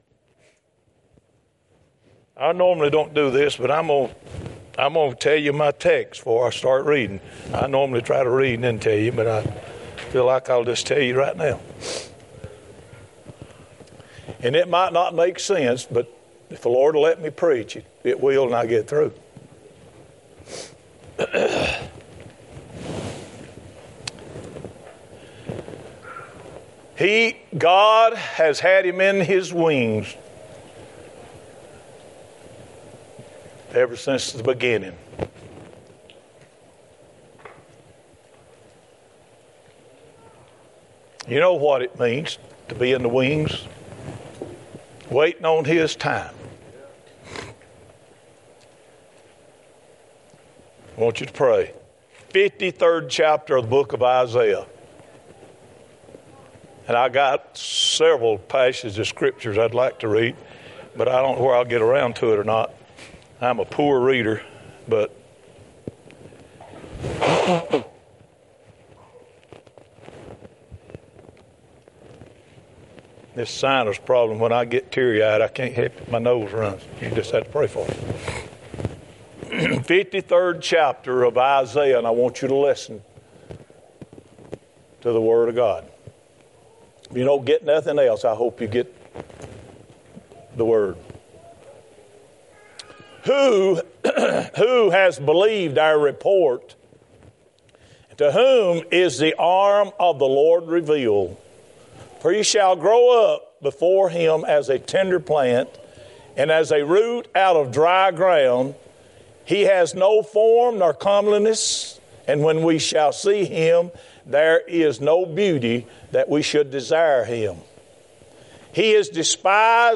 2024 Passage: Isaiah 53:1-12 Service Type: Sunday Topics